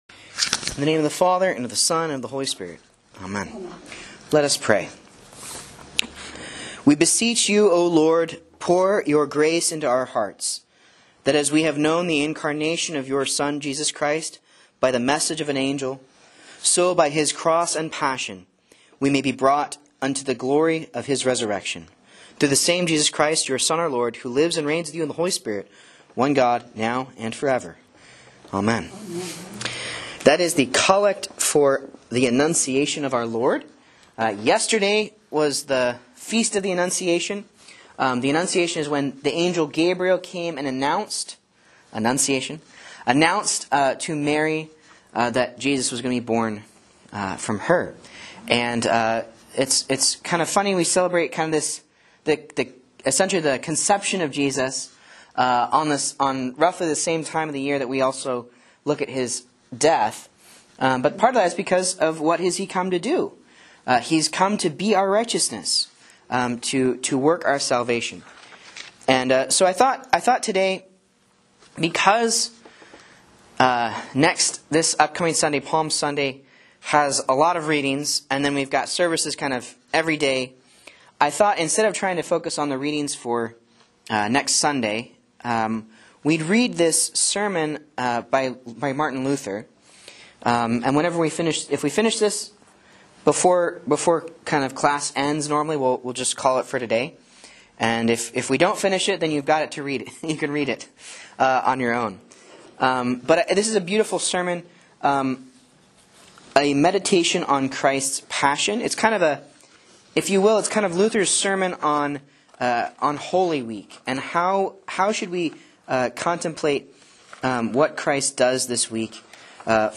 A Bible Class Looking Forward to Holy Week